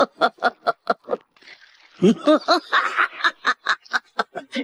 Goku Black Laughing Sound Effect Download: Instant Soundboard Button
1. Play instantly: Click the sound button above to play the Goku Black Laughing sound immediately in your browser.